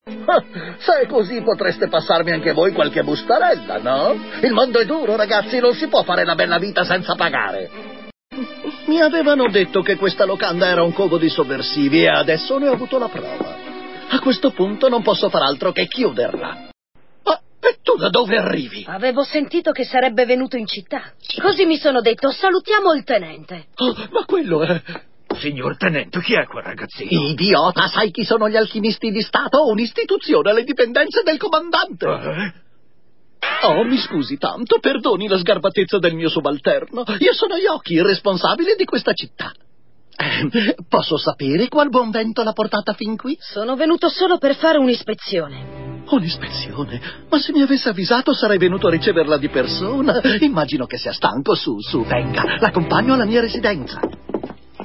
nel cartone animato "FullMetal Alchemist", in cui doppia il serg. Yoki.